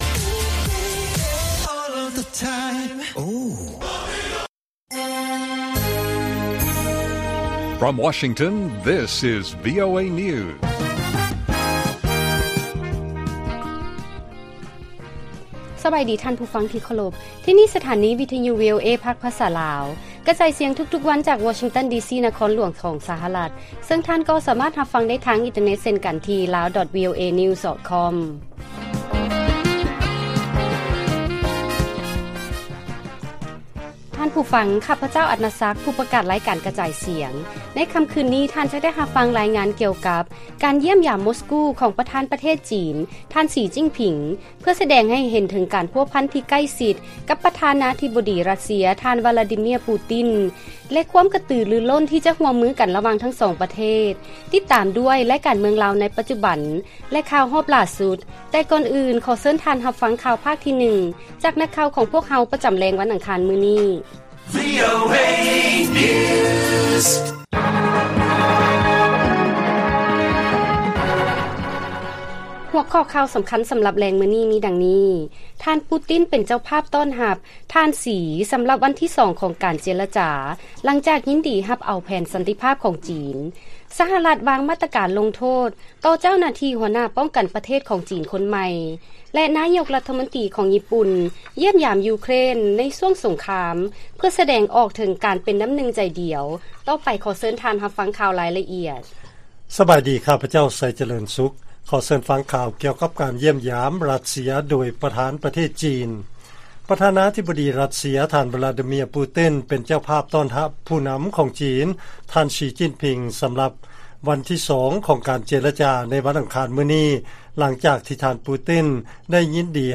ລາຍການກະຈາຍສຽງຂອງວີໂອເອ ລາວ: ທ່ານ ປູຕິນ ເປັນເຈົ້າພາບຕ້ອນຮັບ ທ. ສີ ສຳລັບວັນທີສອງ ຂອງການເຈລະຈາ ຫຼັງຈາກຍິນດີຮັບເອົາ ແຜນສັນຕິພາບ ຂອງຈີນ